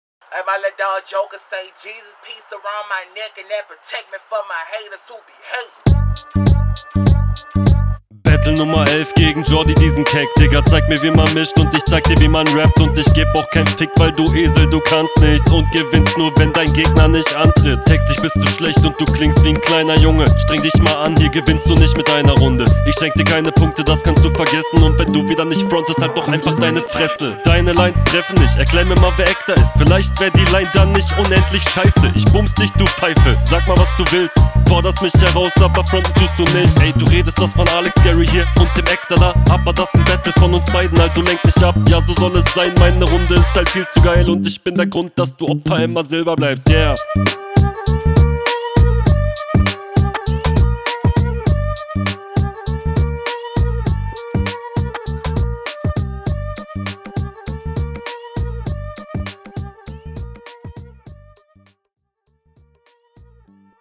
Fand den Style ganz cool und vor allem ist hier aber deine Stimme viel, viel …